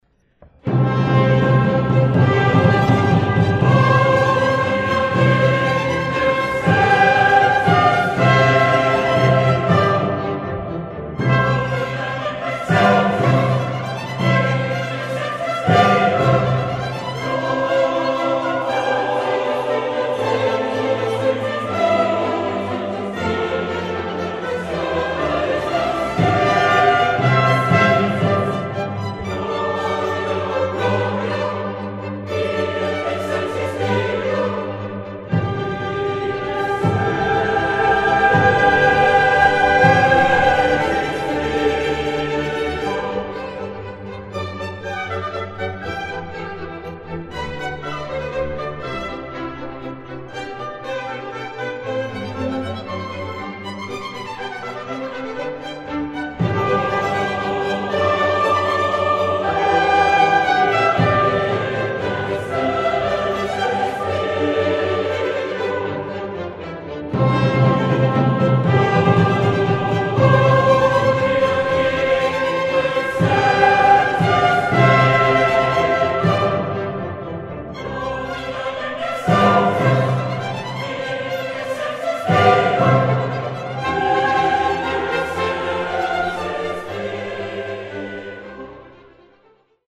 Sopran
Bass
Chor und Orchester von St. Peter